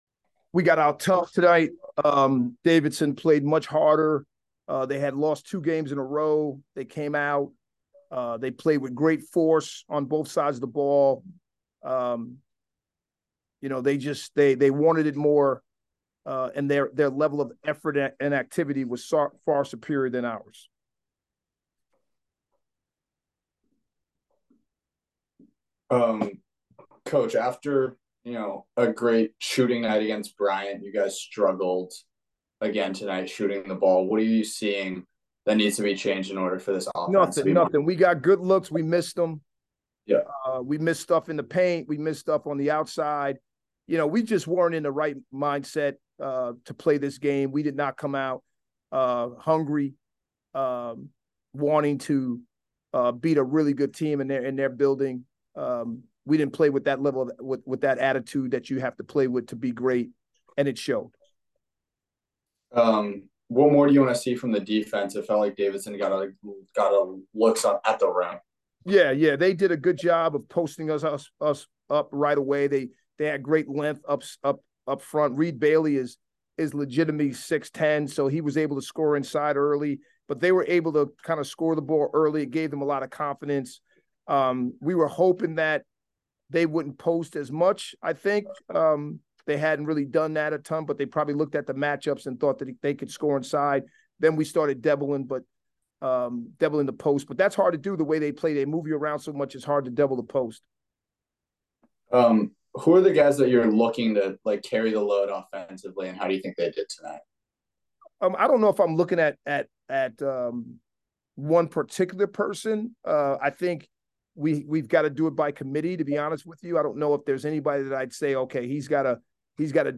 Davidson Postgame Interview